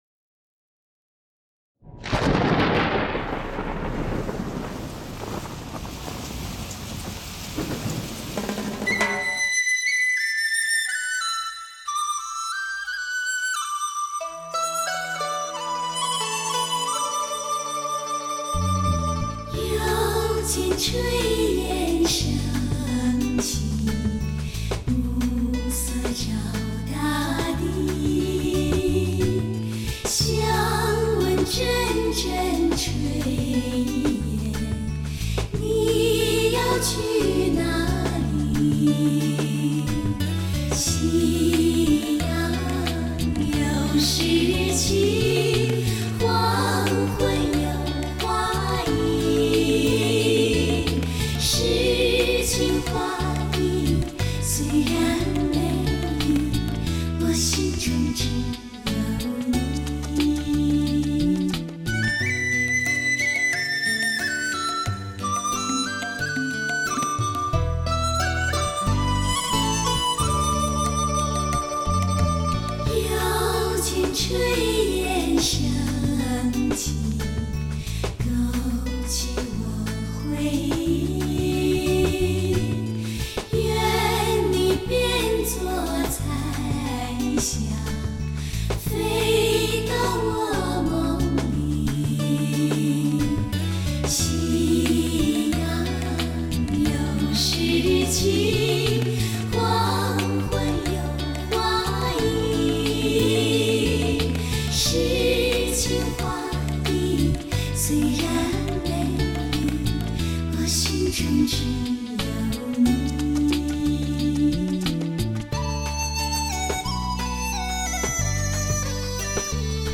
用最新的DSD录音技术灌制而成。